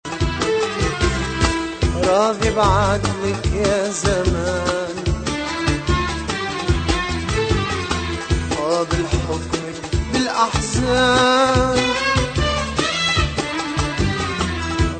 variétés influences orientales